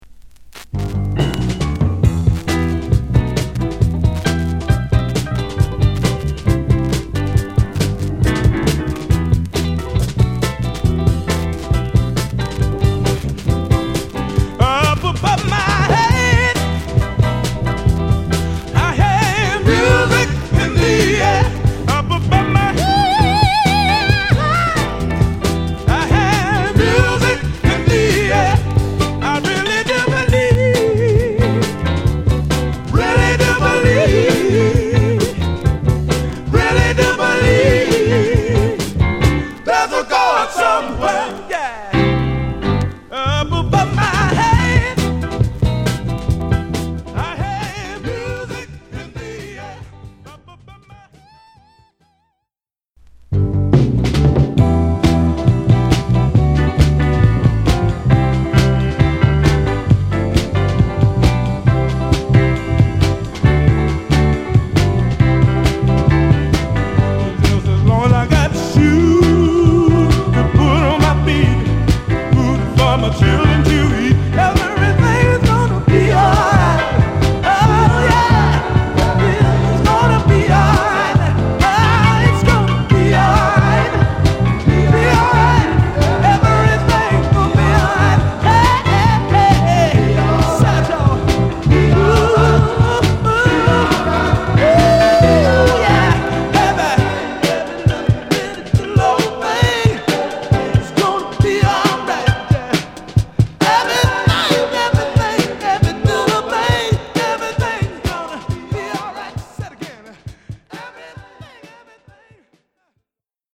兄弟ゴスペルグループ
シッカリしたファンキーな演奏で他のゴスペルより、ソウル〜レアグルーヴ好きも要チェックな彼ら。